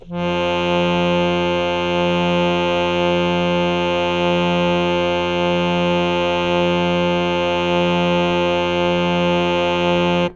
interactive-fretboard / samples / harmonium / F2.wav
F2.wav